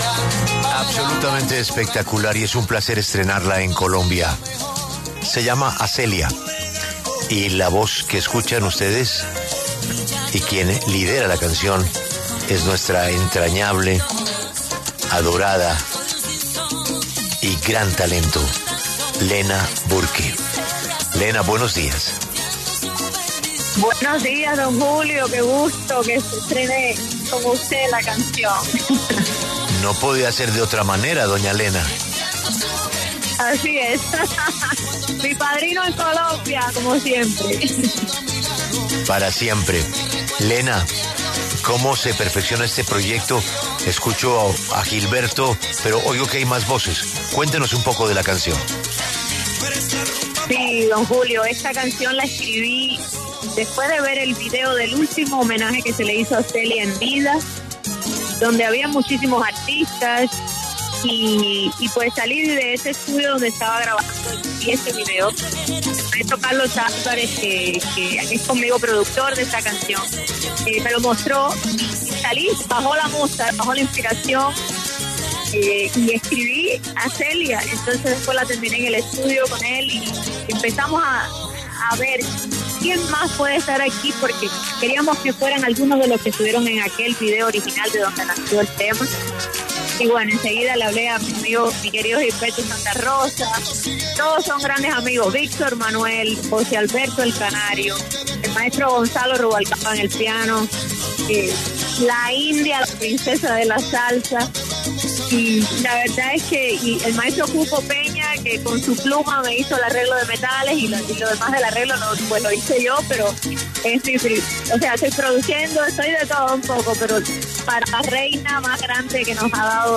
La artista Lena Burke habló en La W sobre el trabajo detrás del proyecto ‘A Celia’, su tributo a la vida y legado de Celia Cruz.
En diálogo con Julio Sánchez Cristo para La W, la cantautora y pianista cubana Lena Burke habló sobre ‘A Celia’, su tributo a la vida y legado de Celia Cruz, la icónica ‘Guarachera de Cuba’.